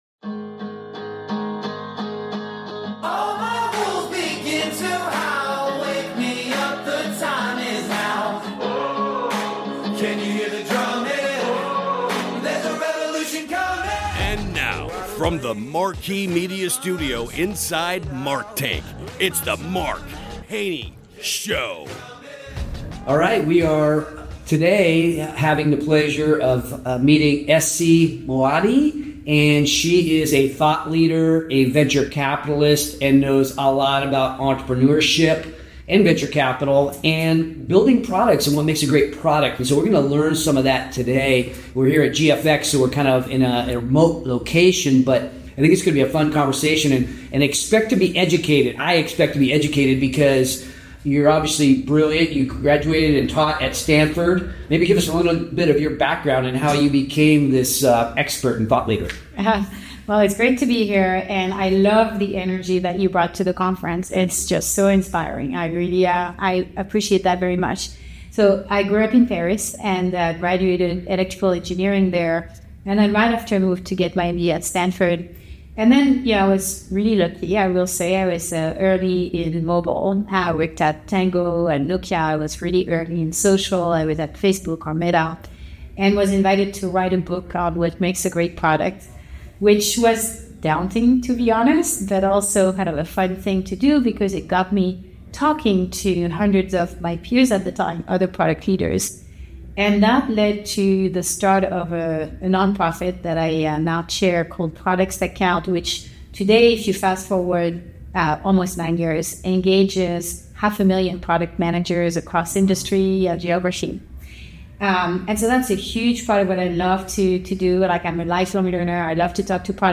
AI, Product Innovation, and Impact: An Interview